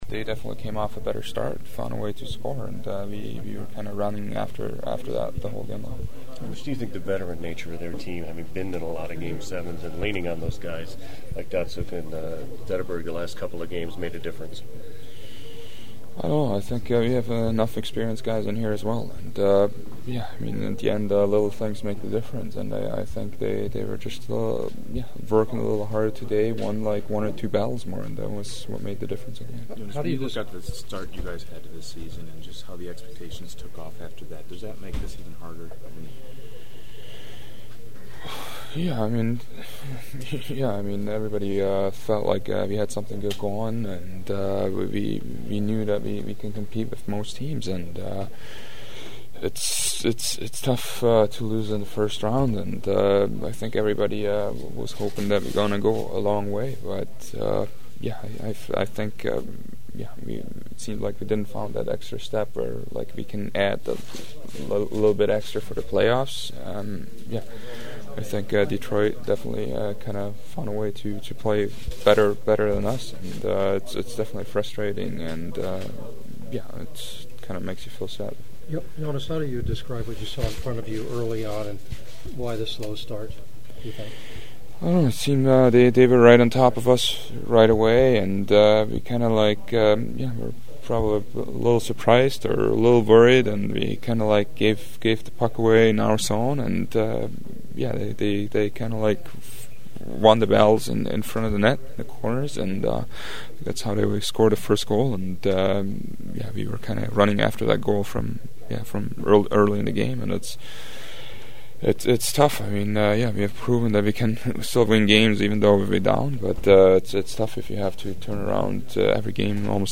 The Ducks had an NHL best 15 come from behind wins during the regular season but their magic also ran out something that was one of my themes of questioning in the postgame locker room.
Ducks goalie Jonas Hiller on his tough way to end the season: